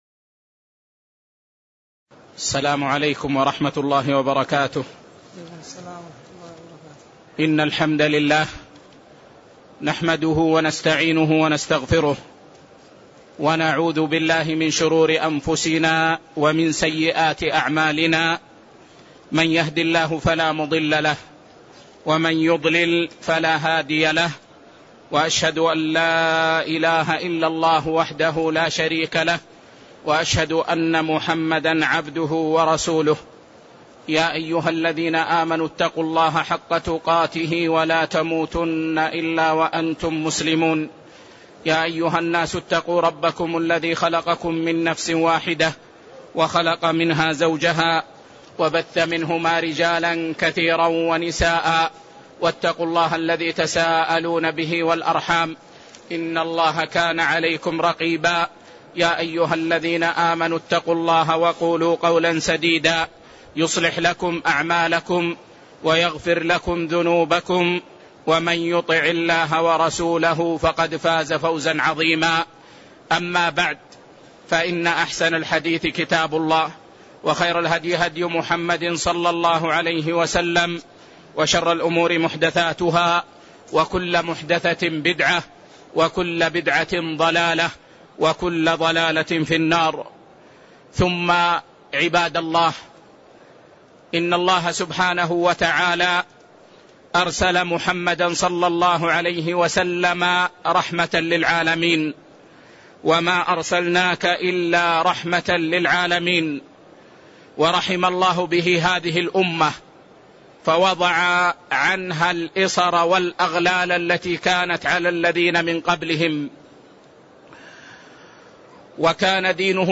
تاريخ النشر ٢٩ ذو القعدة ١٤٣٤ هـ المكان: المسجد النبوي الشيخ